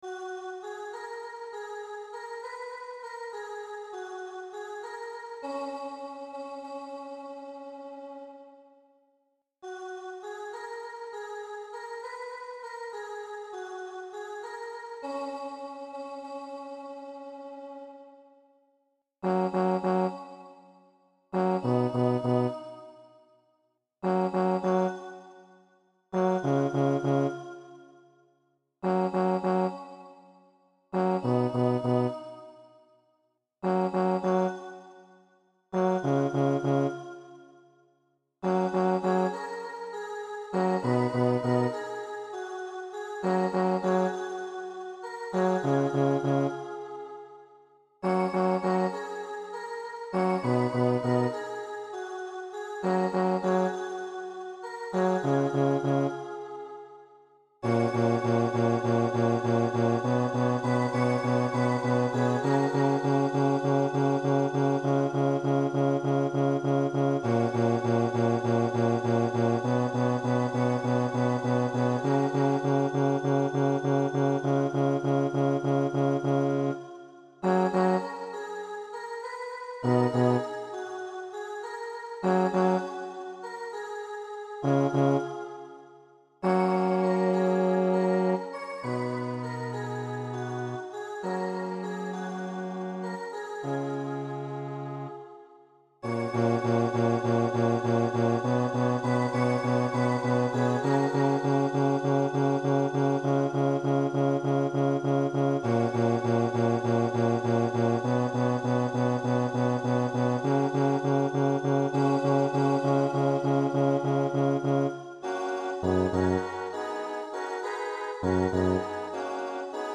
The Lion Sleeps Tonight Baix